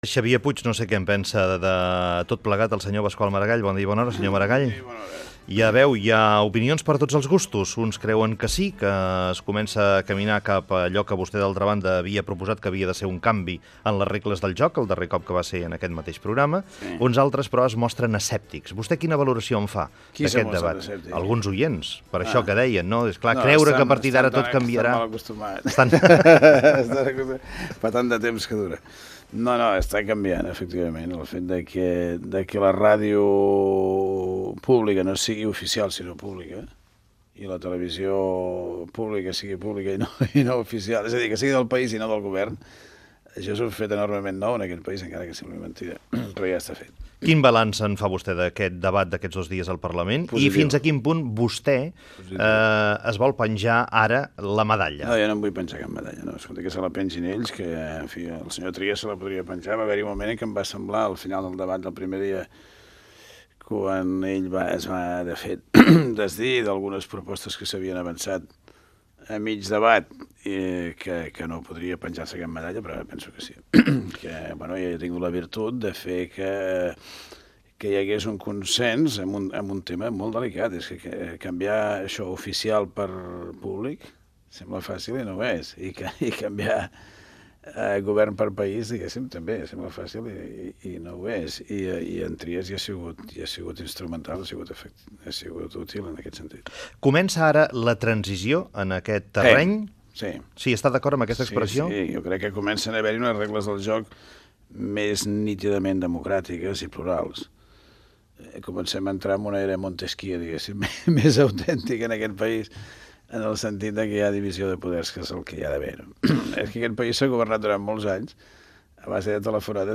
Fragment d'una entrevista al polític Pasqual Maragall, sobre els mitjans de comunicació públics i la CCRTV.
Info-entreteniment